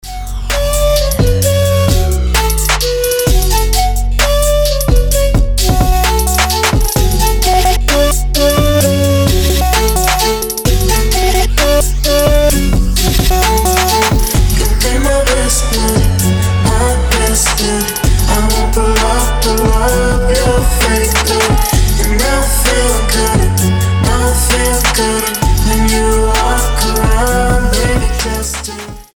• Качество: 320, Stereo
женский вокал
атмосферные
Trap
басы
духовые
Tribal Trap